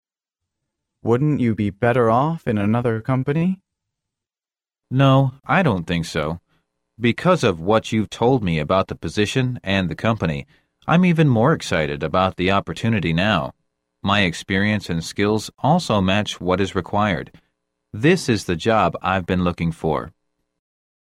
真人朗读，帮助面试者迅速有效优化面试英语所需知识，提高口语能力。